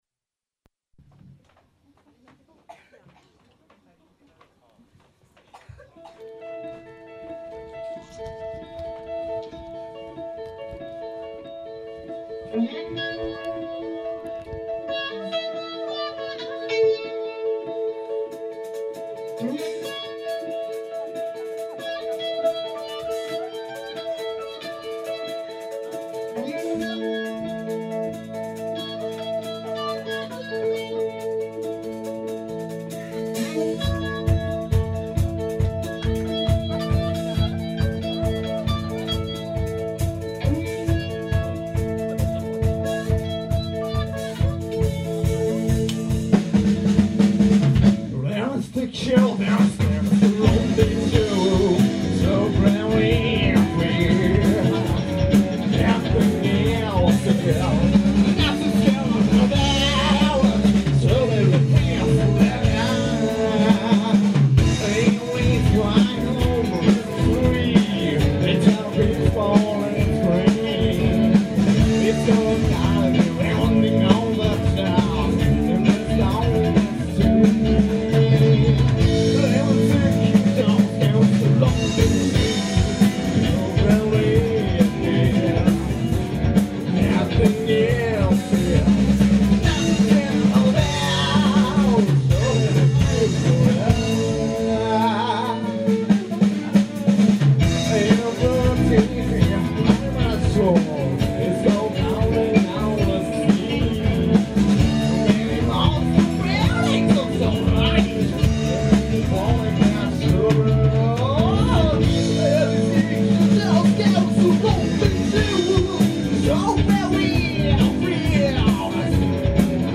全体的にノリも良くてなかなか良かったんじゃないでしょうか。